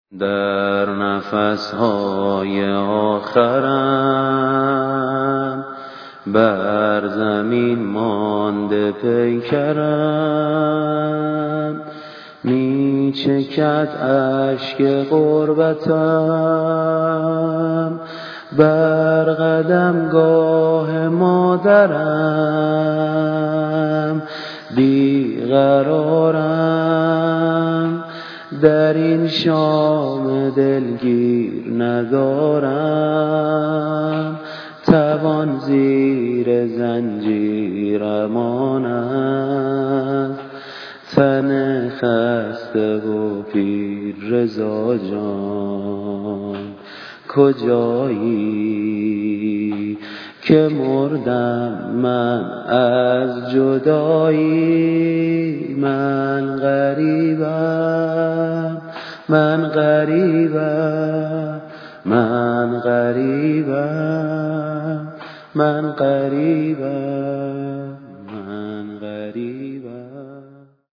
واحد ، نوحه